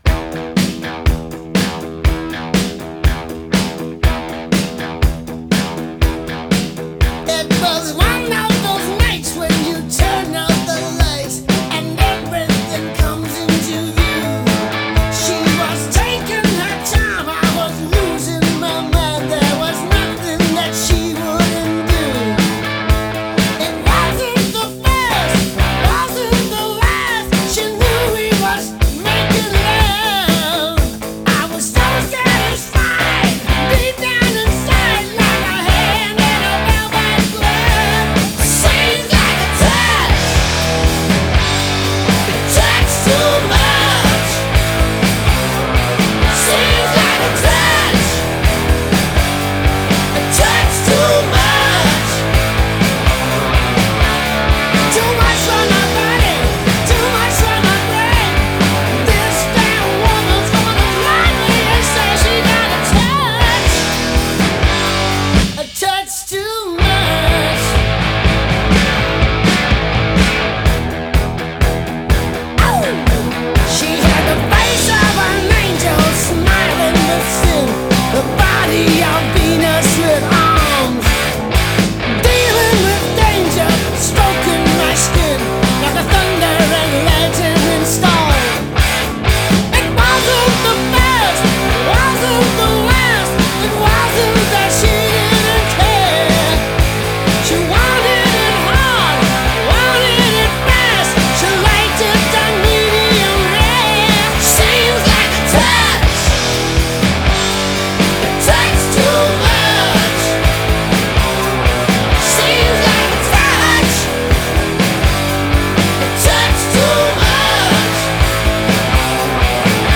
آهنگ راک Hard Rock هارد راک